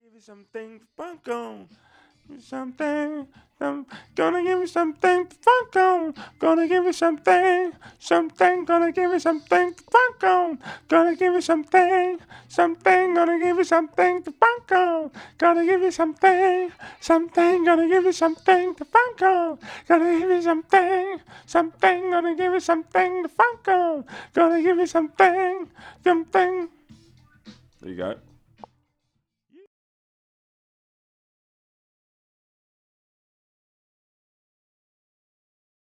DF_107_E_FUNK_VOX_02 .wav